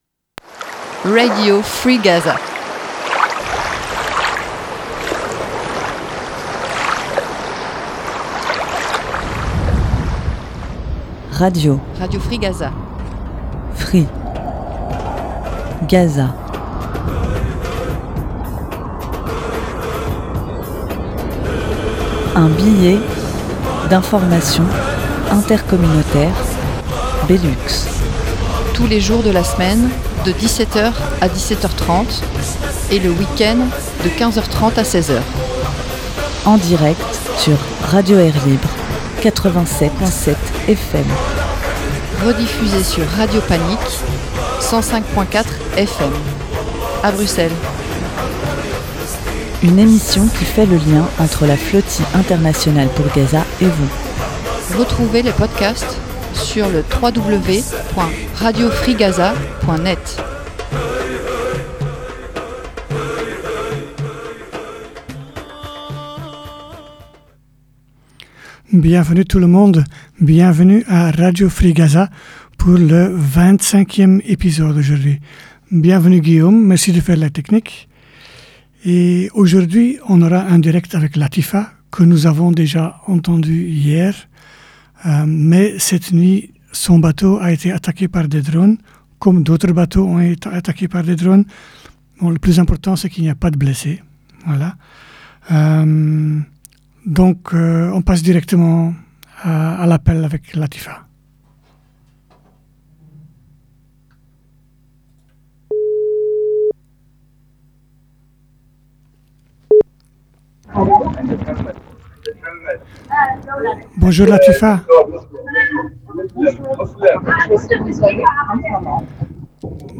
Un direct avec une participante d’un des bateaux qui a été attaqué.